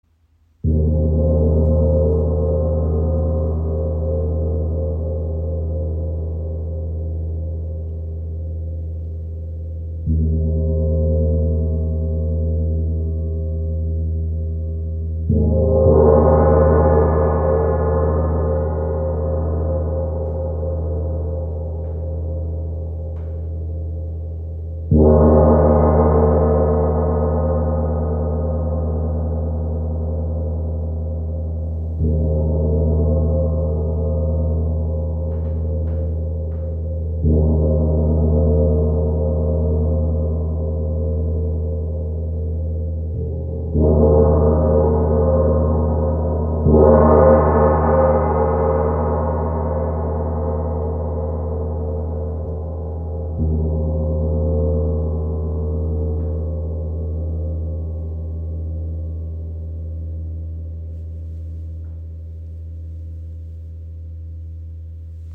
• Icon Klarer, kraftvoller Klang – reinigt Raum, Aura und Geist, ideal für Meditation und Klangheilung
Universe Gong | Violet Flame | ø 60 cm
Violet Flame Gong (Ø 60 cm, 2,6 kg) – Handgefertigt in Portugal, leicht spielbar mit kraftvollem Klang für Reinigung, Meditation und Klangheilung.
Sein klarer, resonanter Klang öffnet Herz und Geist, harmonisiert Energie und lädt zu Meditation, Klangheilung und bewusster Energiearbeit ein.
Jeder Anschlag entfaltet die Magie der violetten Flamme – eine sanfte, heilende Schwingung, die Dich in Einklang mit Dir selbst und Deiner Umgebung bringt.
• Material: Edelstahl